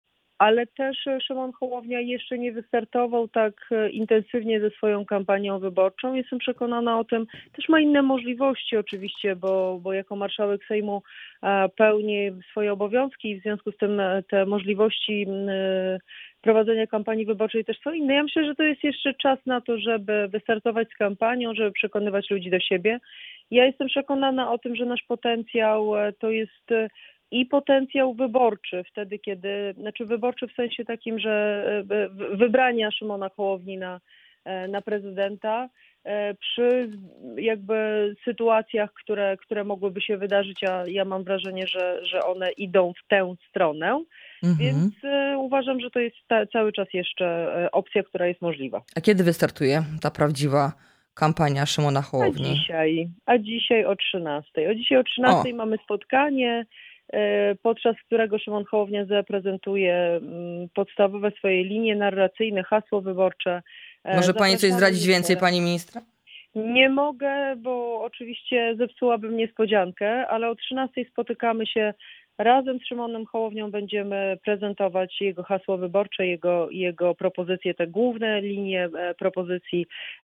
Wiceminister Joanna Mucha w audycji „Poranny Gość” zapowiedziała start kampanii Szymona Hołowni.